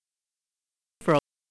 【ou】の母音については、0.05秒〜0.06秒のみ聞こえるようにしてあります。
※この短い時間では【ou】の【u】へ変化は含まれません。
オリジナルの音声はサイト"the speech accent archive"のenglish2です。